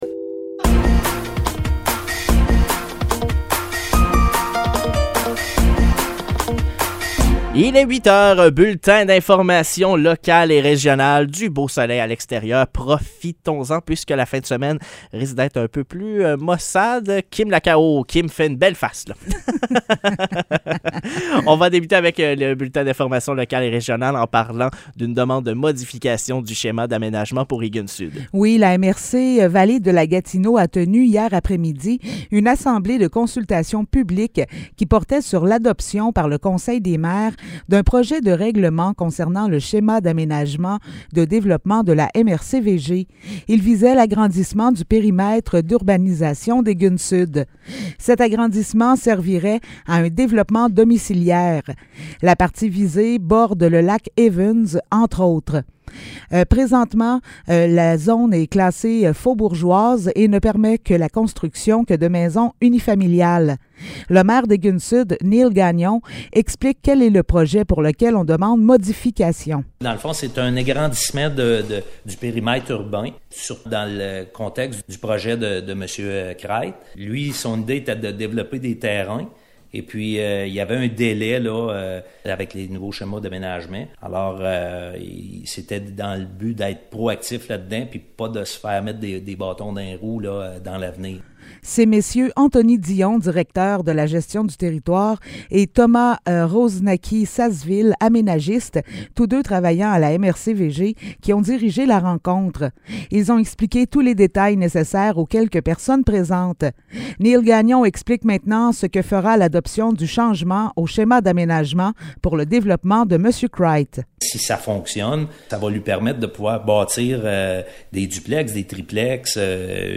Nouvelles locales - 28 avril 2023 - 8 h